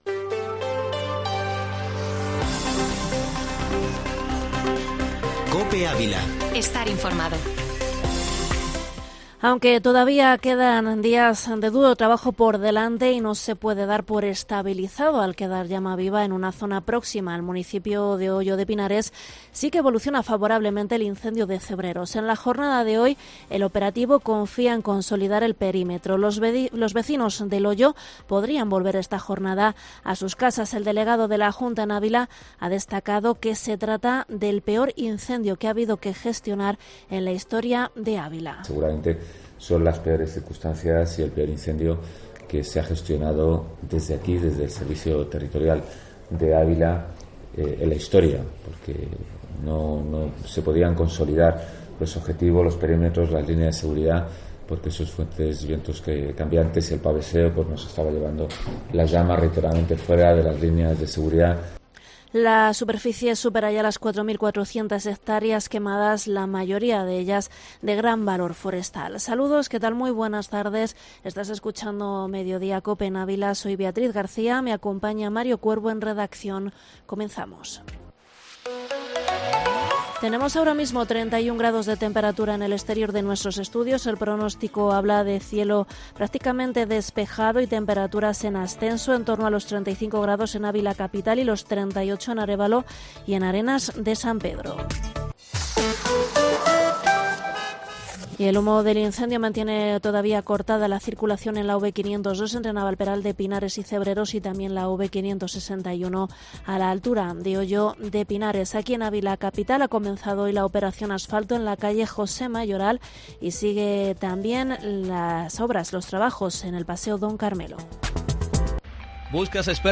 informativo Mediodía COPE ÁVILA Foto: Naturaleza CyL